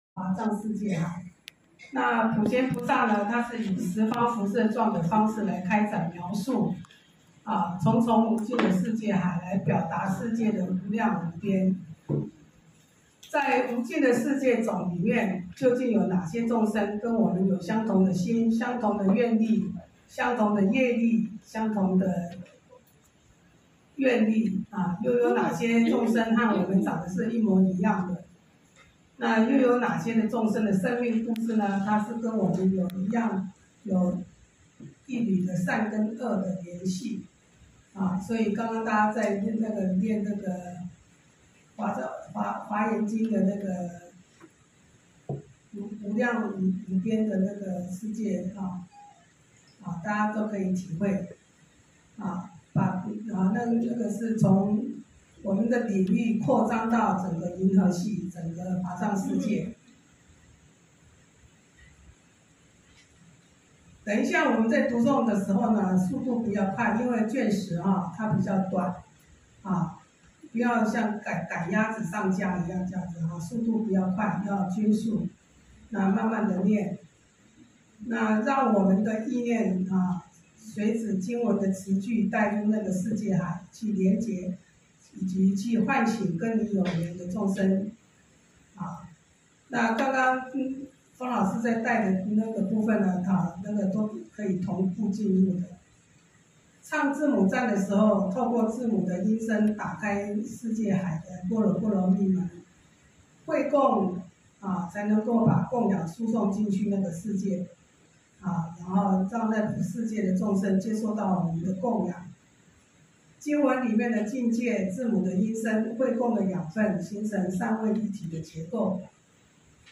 週六共修---讀誦華嚴經第10卷